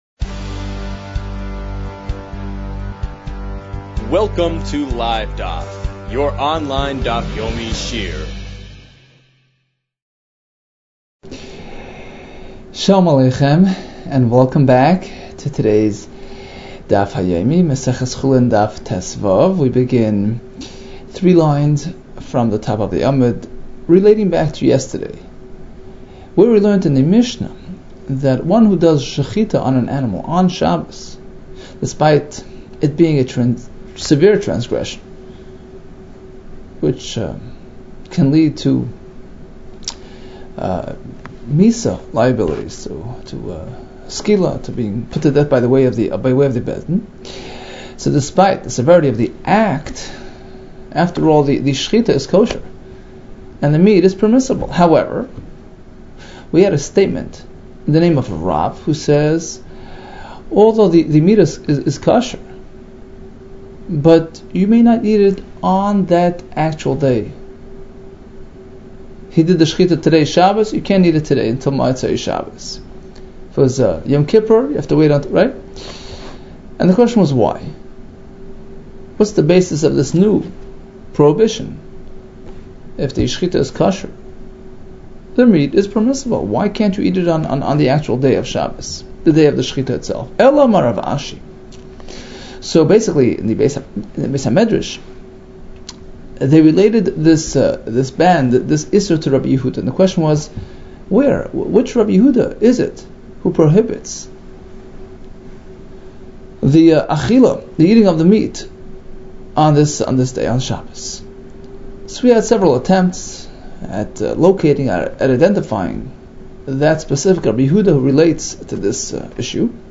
Chulin 14 - חולין יד | Daf Yomi Online Shiur | Livedaf